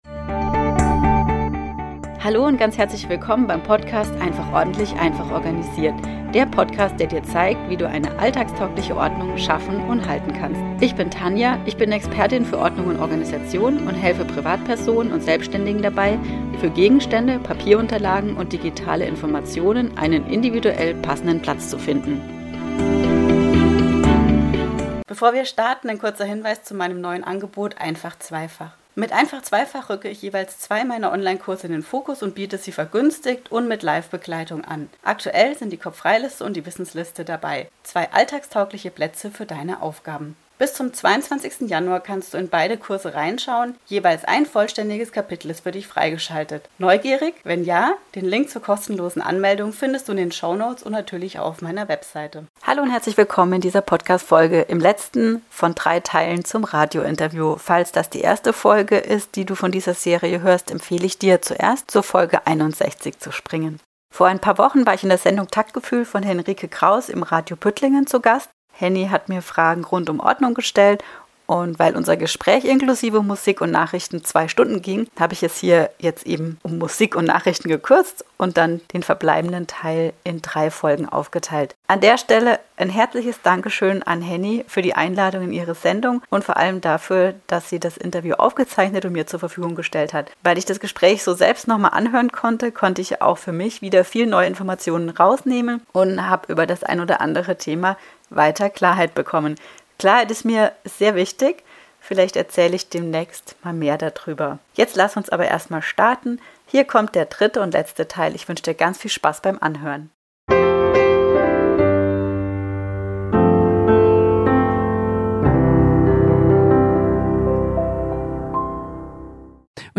Radiointerview Teil 3 (Folge 63) ~ einfach ordentlich - einfach organisiert Podcast
Als Gast in der Sendung Taktgefühl bei Radio Püttlingen